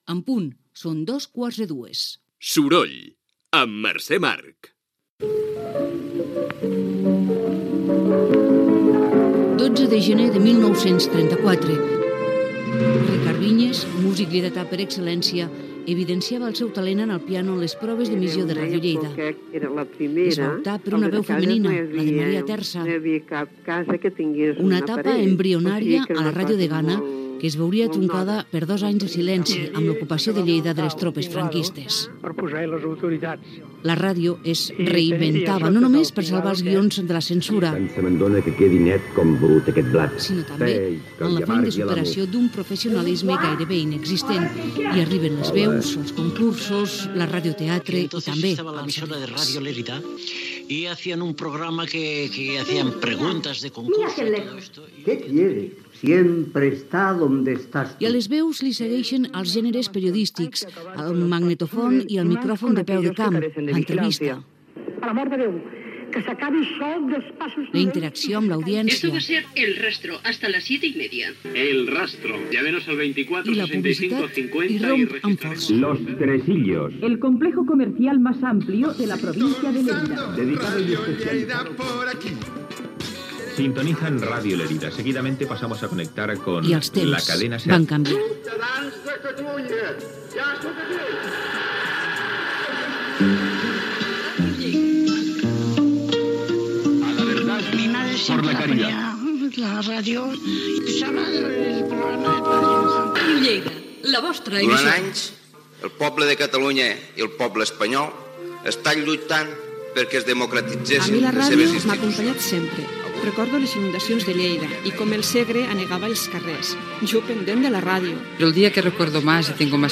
Hora, indicatiu del programa. 84 aniversari de Ràdio Lleida, que va començar a emetre el 12 de gener de 1934. Apunt històric i recull de fragments breus de l'emissora.
Informatiu